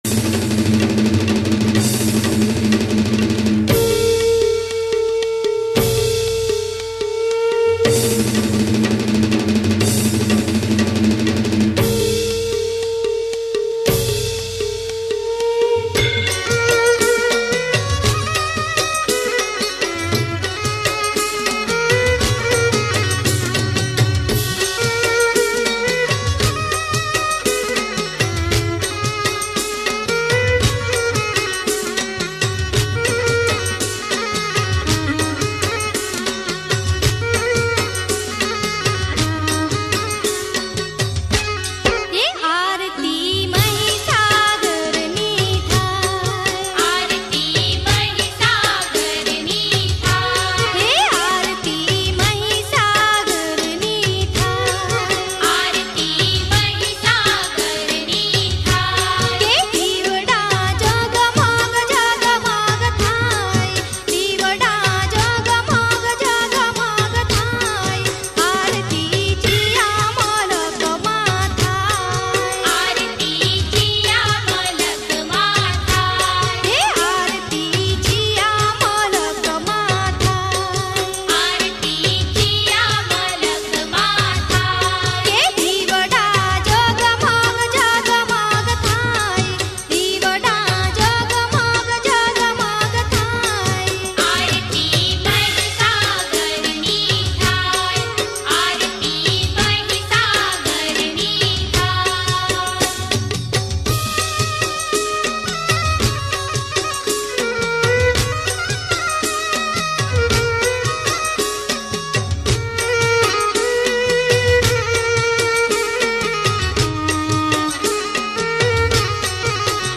Gujarati Aarti
Bhakti